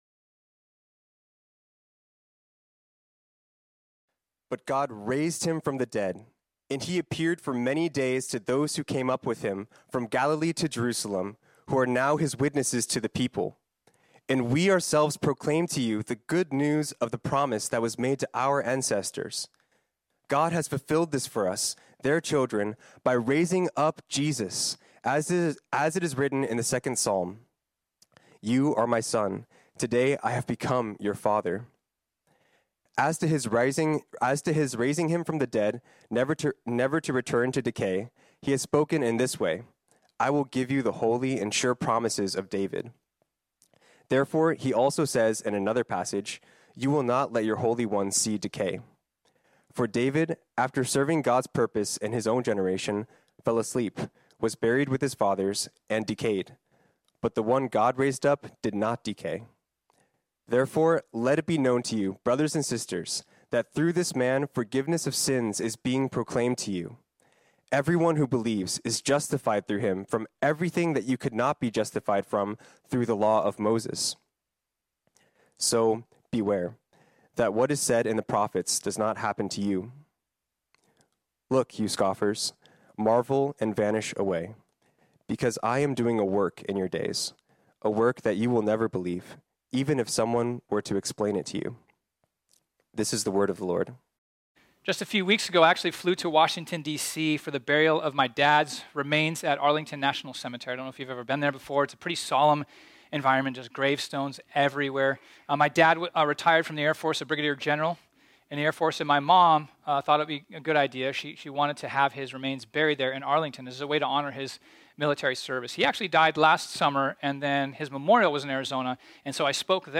This sermon was originally preached on Sunday, April 9, 2023.